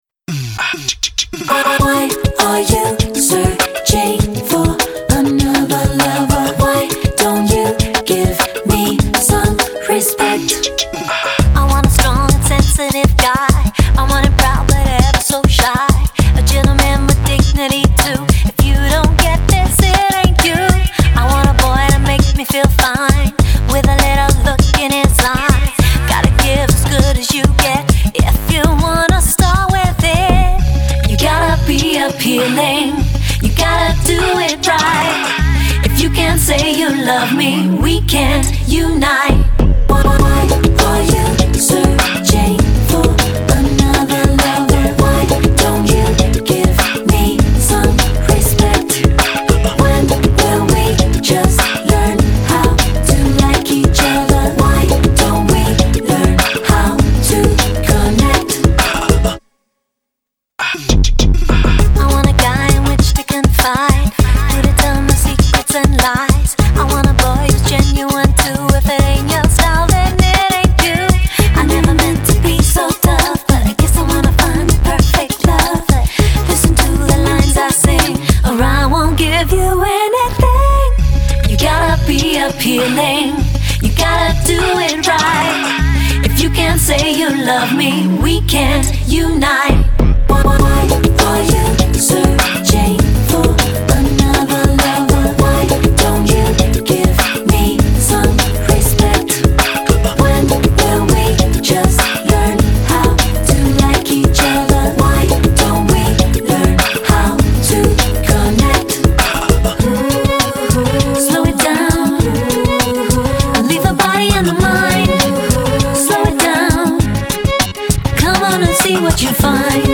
专辑风格：Pop